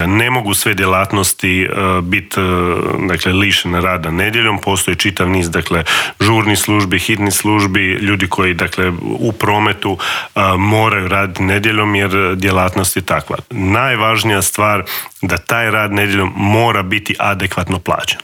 ZAGREB - Gostujući u Intervjuu tjedna Media servisa ministar rada, mirovinskoga sustava, obitelji i socijalne politike Marin Piletić otkrio je detalje pregovora sa sindikatima oko povišica, ali se osvrnuo i na najavu zabrane rada nedjeljom, kritikama na novi Zakon o radu, kao i o novostima koje stupaju na snagu 1. siječnja, a tiču se minimalne plaće i mirovina.